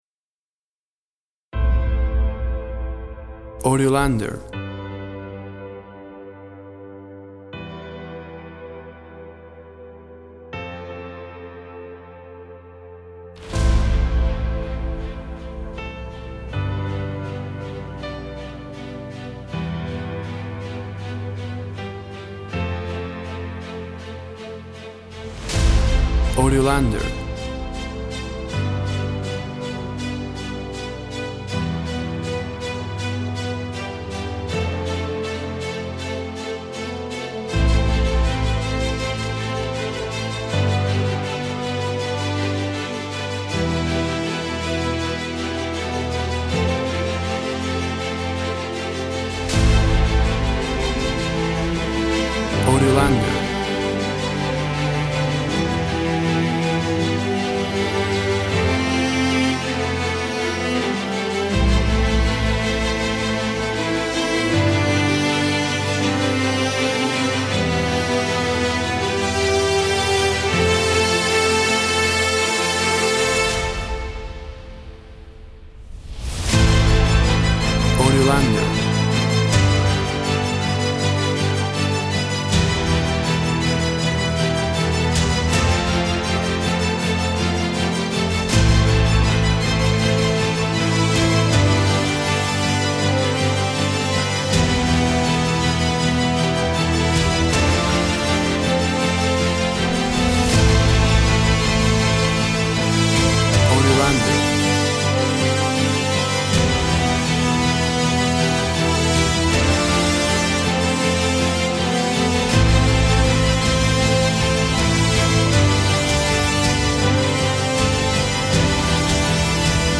Tempo (BPM) 160